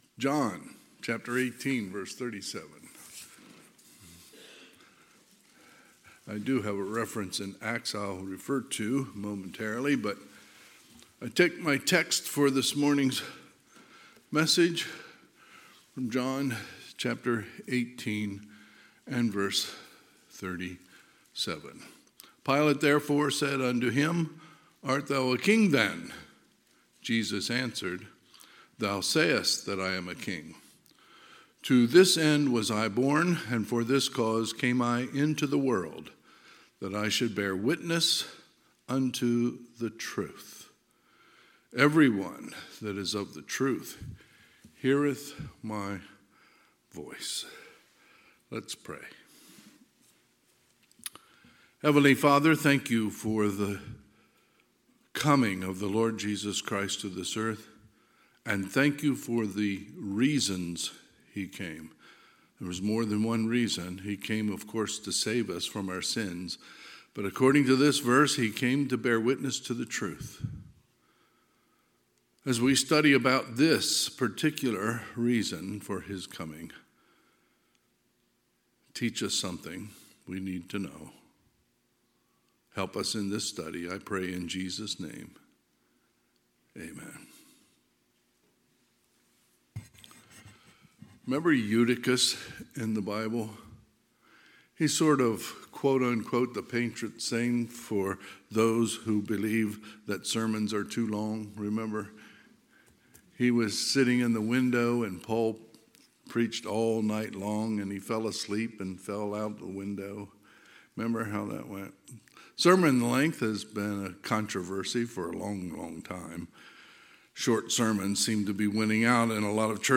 Sunday, December 10, 2023 – Sunday AM